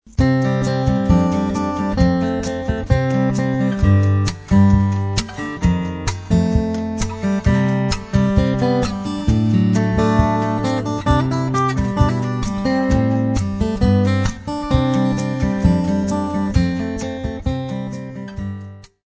chitarra acustica, percussioni.
basso elettrico, basso fretless.